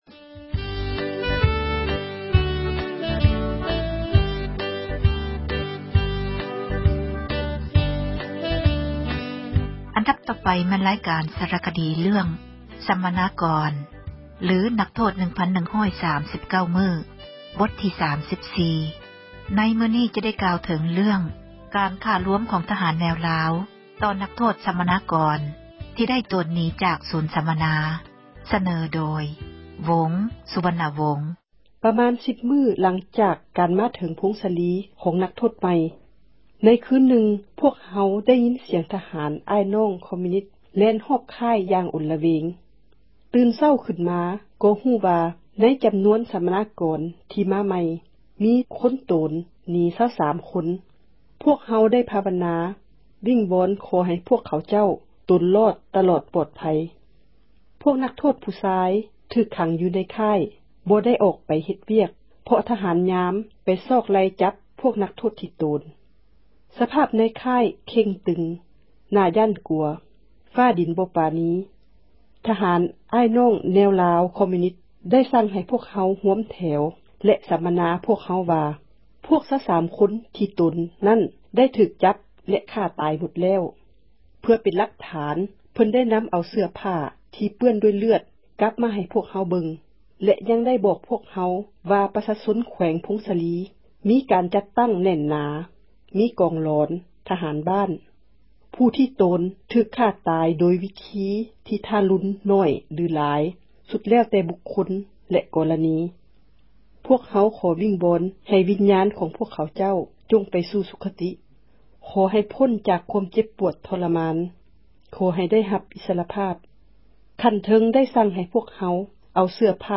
ຣາຍການ ສາຣະຄະດີ ເຣຶ້ອງ “ສັມມະນາກອນ ຫຼື ນັກໂທດ 1139 ມື້ ບົດທີ່ 34. ມື້ນີ້ ຈະໄດ້ ກ່າວເຖີງ ເຣື້ອງ ການ ຂ້າລວມ ຂອງທະຫານ ແນວລາວ ຕໍ່ນັກໂທດ ສັມມະນາກອນ ທີ່ ໄດ້ໂຕນ ໜີ ຈາກ ສູນ ສັມມະນາ.